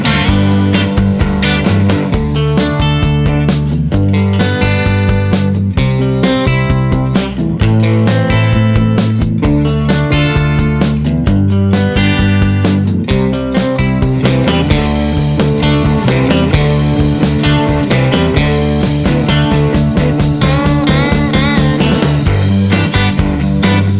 Minus All Guitars Pop (1960s) 2:57 Buy £1.50